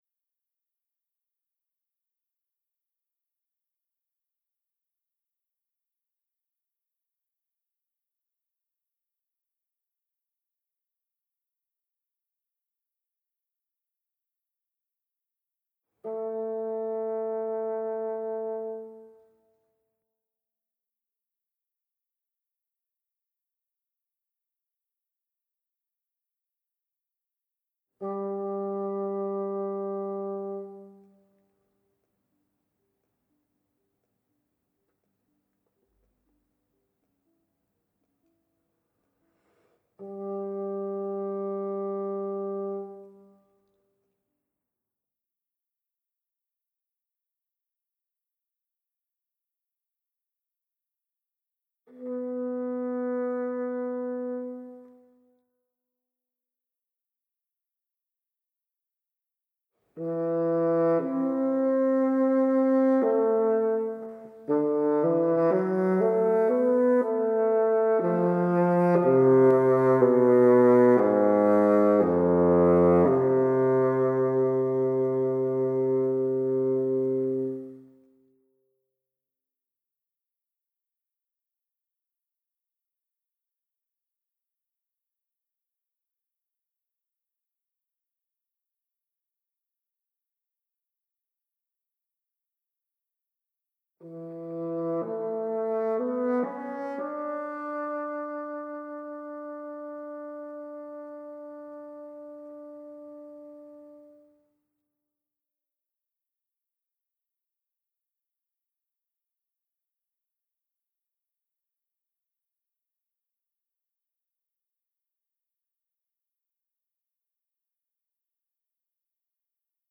SWAN STEM08 BASSOON.mp3